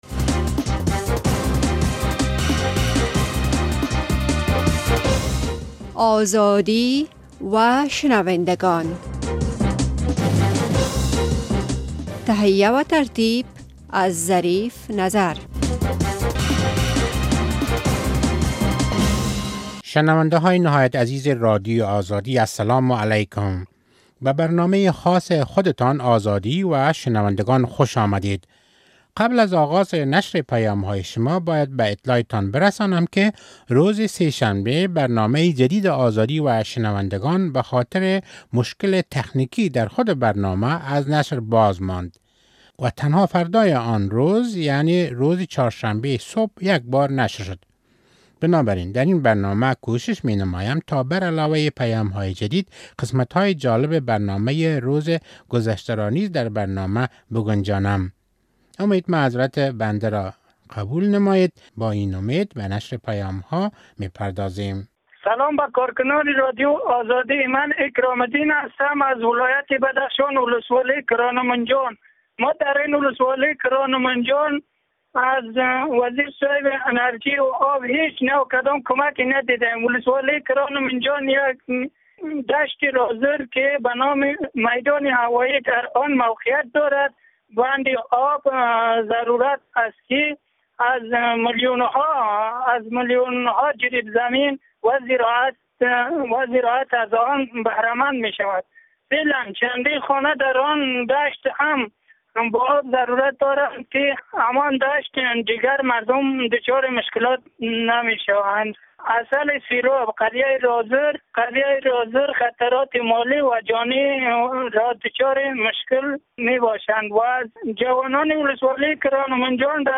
در این برنامهء آزادی و شنونده گان نیز پیام های نشر می شود که شنونده گان رادیوآزادی از طریق تلیفون هایشان در پیام گیر این برنامه گذاشته اند. شنونده گان رادیو آزادی مشکلات شان را مطرح کرده، با ارایه پیشنهادات شان از مسوولین امور نیز انتقاد کرده اند.